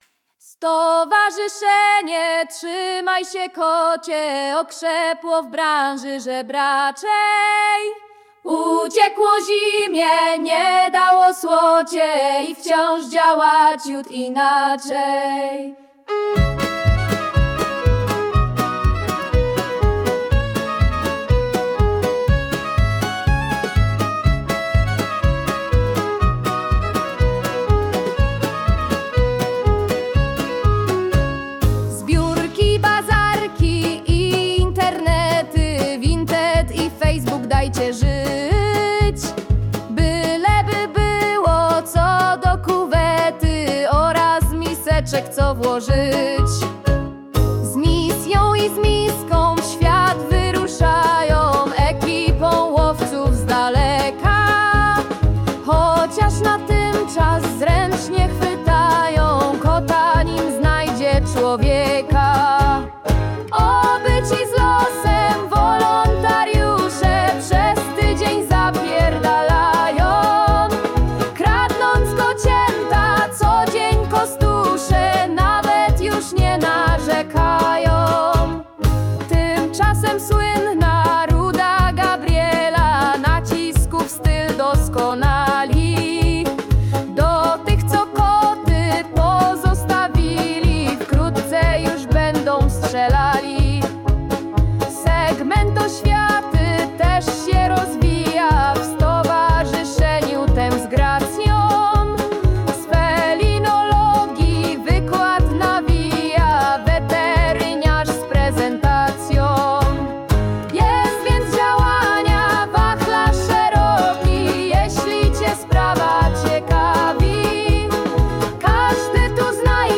Powyższy utwór przerobiony przez AI na piosenkę.